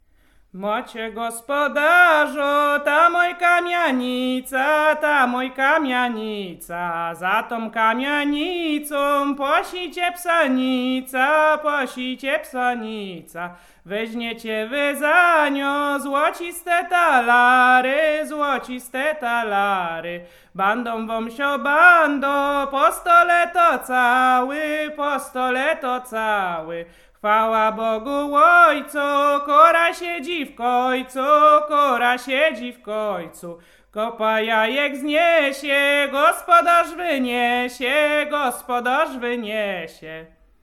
Dyngusowa
gaik kolędowanie kolędowanie wiosenne maik wiosenne wiosna dyngus dyngusowe wielkanoc